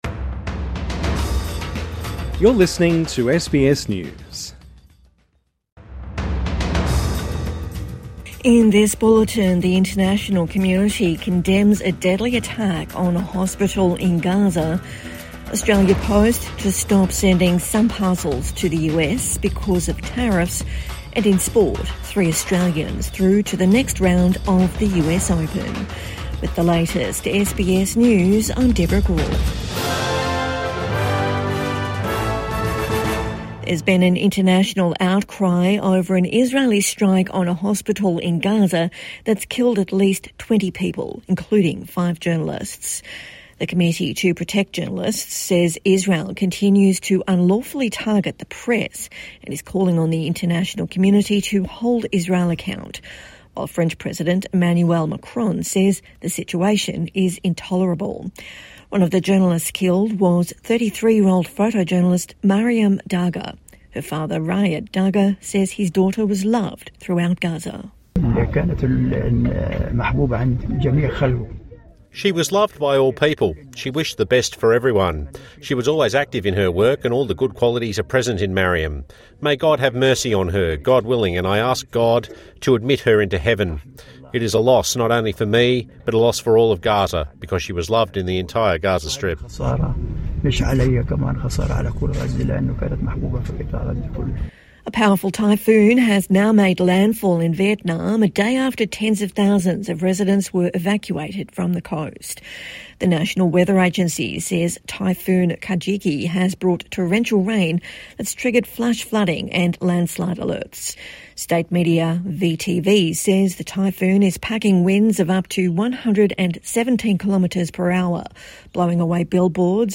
Condemnation continues over deadly Israeli air strike on Gaza hospital | Midday News Bulletin 26 August 2025